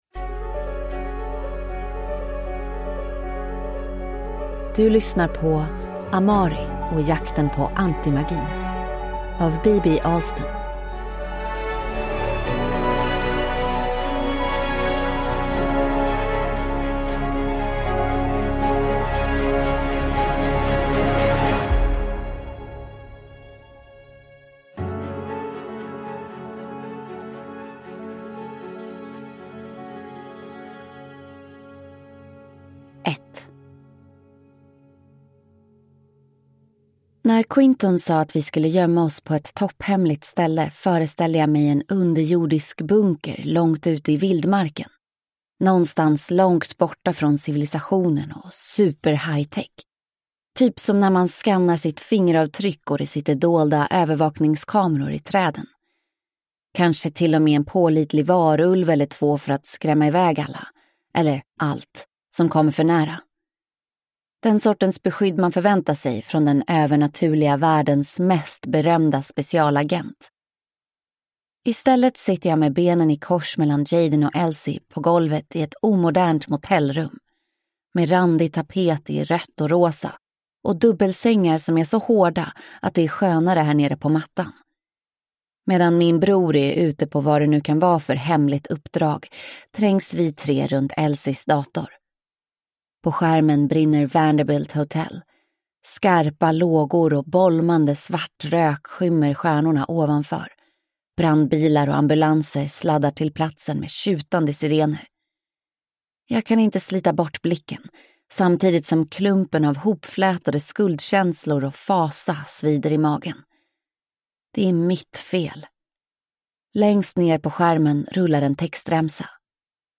Amari och jakten på antimagin – Ljudbok